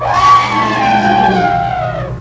c_elep_dead.wav